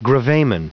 Prononciation du mot gravamen en anglais (fichier audio)
Prononciation du mot : gravamen